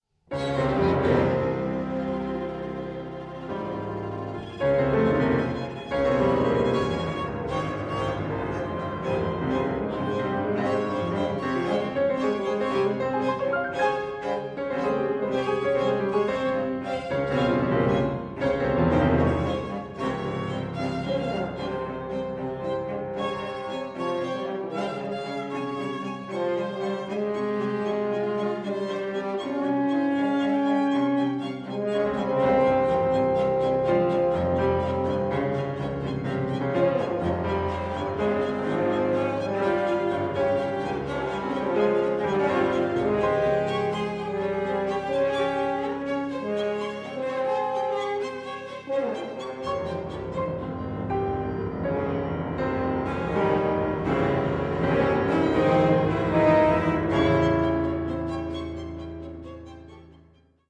horn
violin
viola
cello
piano
percussion